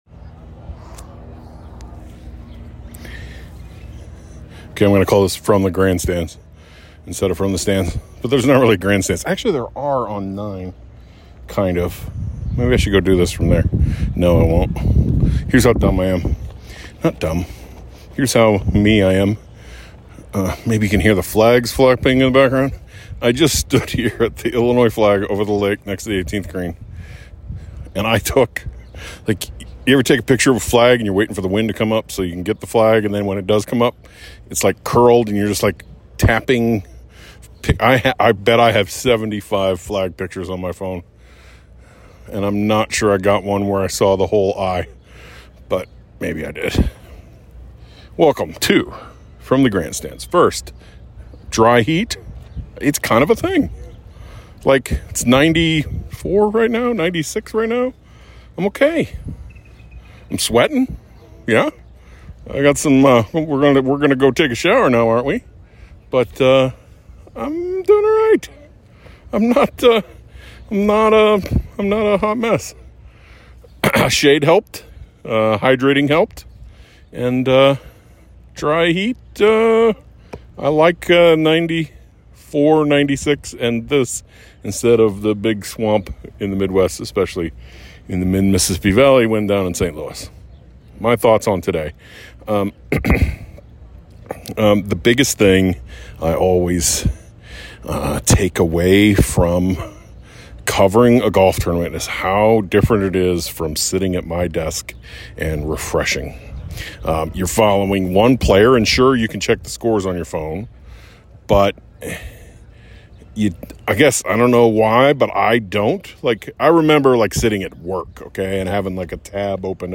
This is a very short bonus episode. It's my quick thoughts after the first round of the NCAA Golf Championship followed by a quick interview